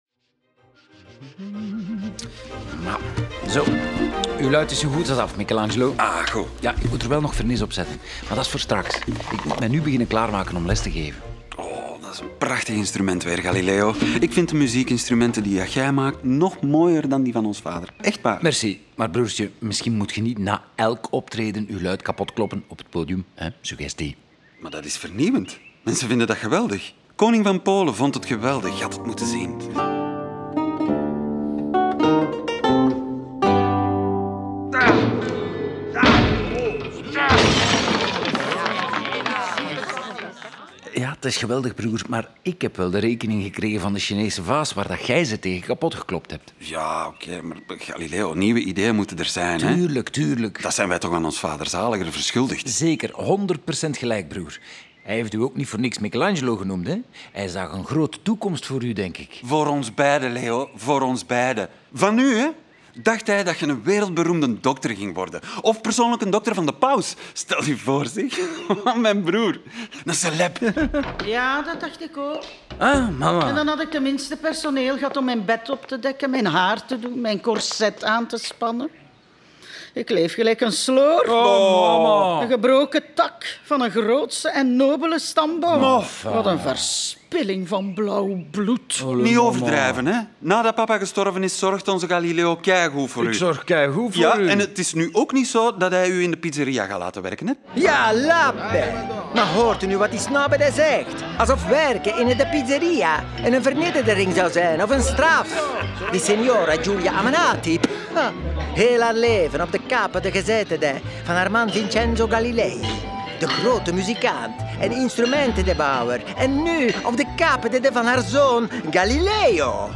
In dit experimenteel interplanetair hoorspel volgen we Galileo’s opgang en de opwinding die hij bij studenten, wetenschappers, drukkers en enthousiaste biechtvaders veroorzaakte.